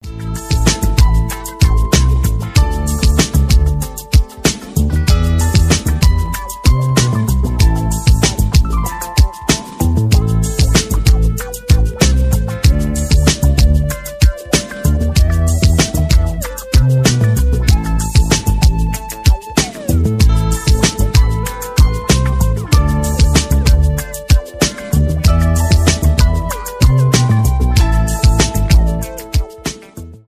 gangsta rap
инструментальные